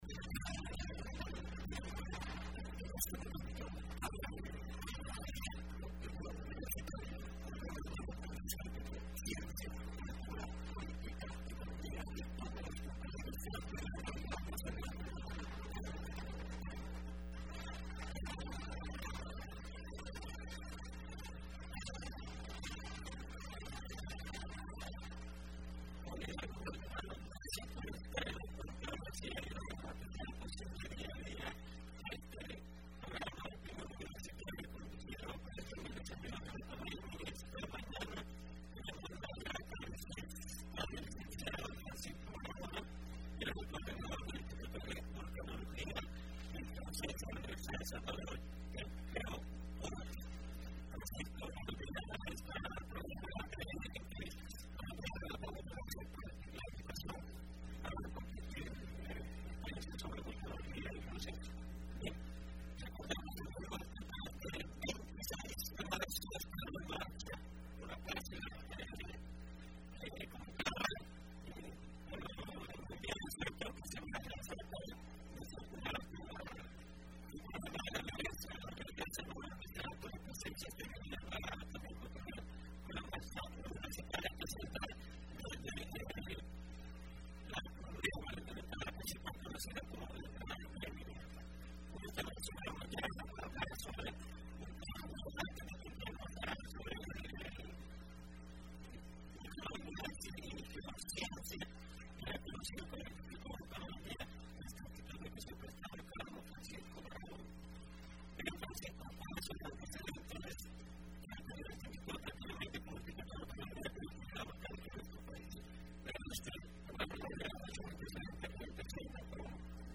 Entrevista Opinión Universitaria (23 de marzo 2015): La incidencia del Instituto de Vulcanología y Geo ciencia de la Universidad de El Salvador en la prevención de la vulnerabilidad en el país.